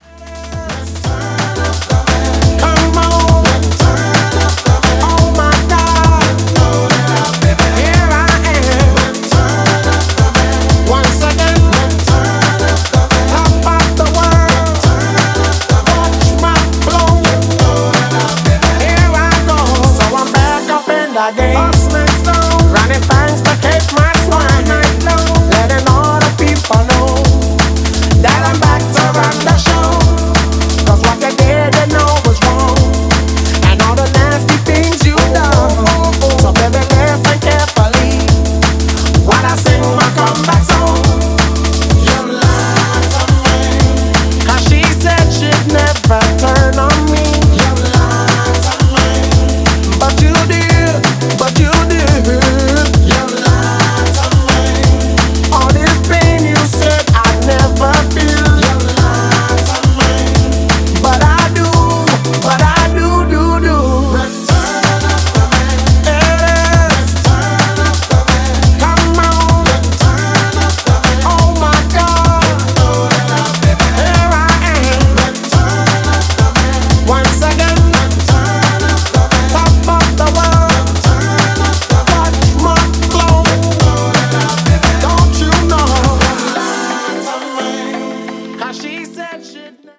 Styl: Drum'n'bass , Jungle/Ragga Jungle